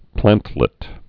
(plăntlĭt)